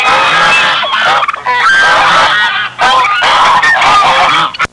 Geese And Ducks Sound Effect
Download a high-quality geese and ducks sound effect.
geese-and-ducks-1.mp3